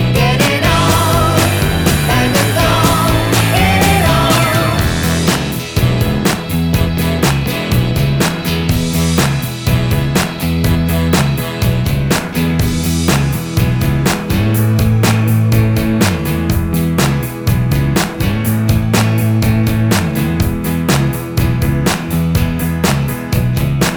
Minus Guitars Glam Rock 4:44 Buy £1.50